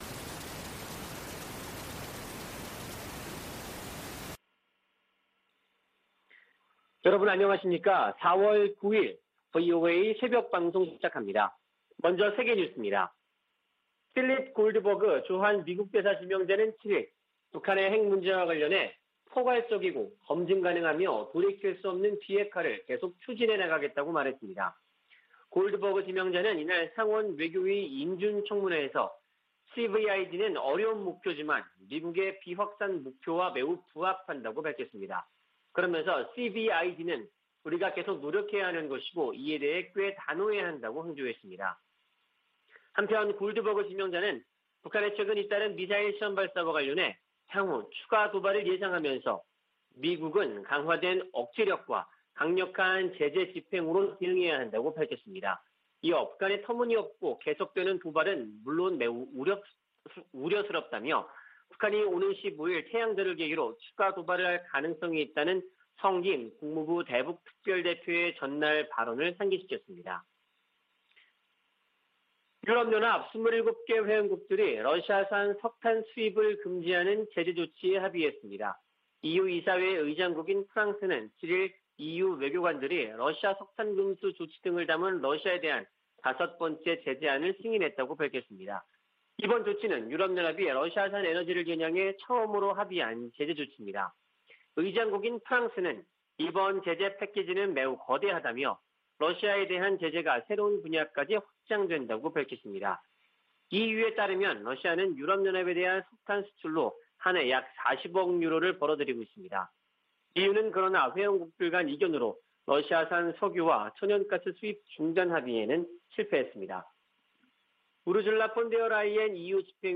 VOA 한국어 '출발 뉴스 쇼', 2022년 4월 9일 방송입니다. 필립 골드버그 주한 미국대사 지명자는 북한의 완전하고 검증 가능하며 돌이킬 수 없는 비핵화(CVID)를 강력 추진해야 한다고 말했습니다.